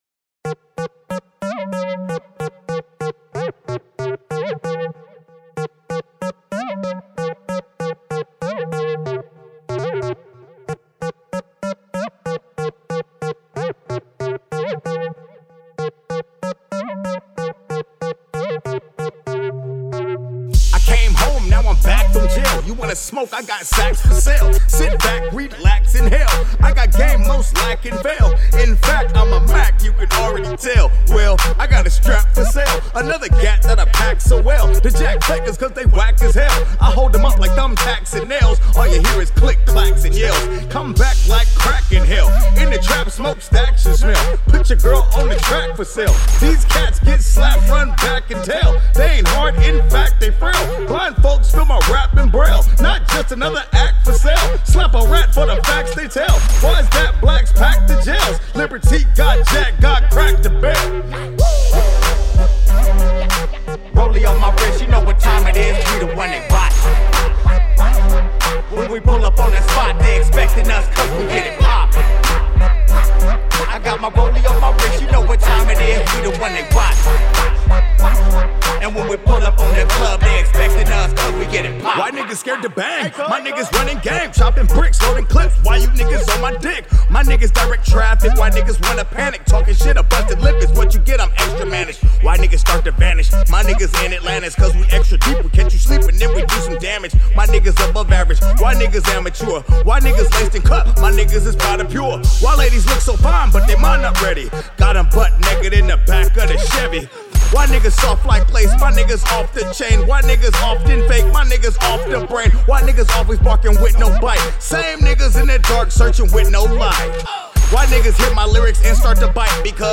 Hiphop